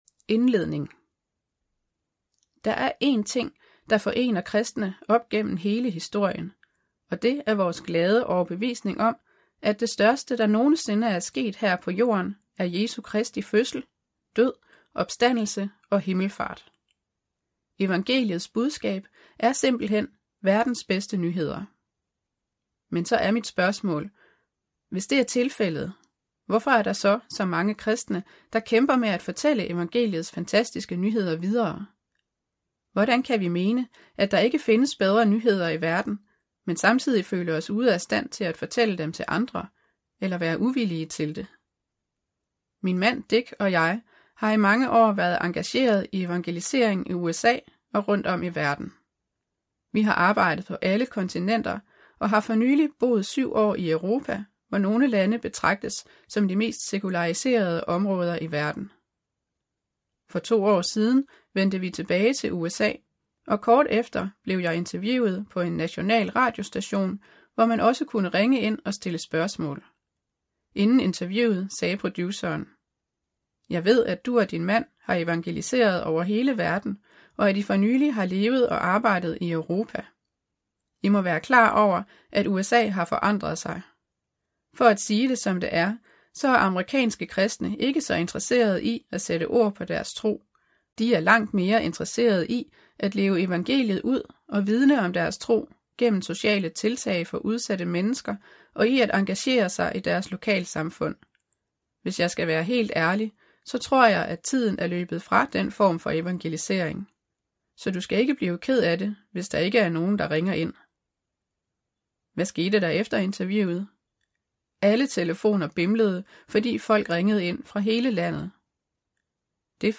Salt – MP3-lydbog – af Rebecca Manley Pippert
Hør et uddrag af Salt Salt Forfatter Rebecca Manley Pippert Bog Lydbog E-bog 199,95 kr.